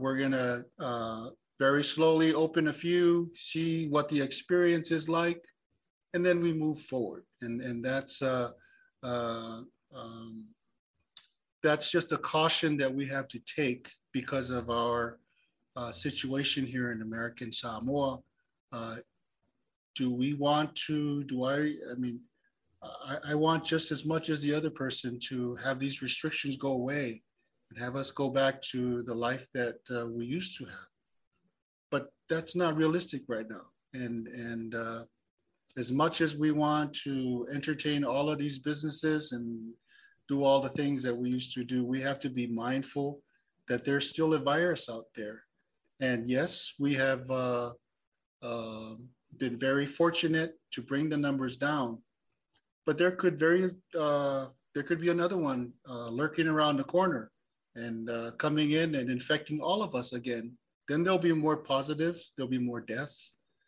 At Monday’s press conference, we asked the Task Force Chairman if it wasn’t easier for all parties concerned to open everything up, since they are allowing some prohibited businesses to re-open, provided they submit a plan that the Task Force approves. Lt. Governor Talauega Eleasalo Ale said that with every emergency declaration issued, they’ve been gradually allowing businesses and activities to open up.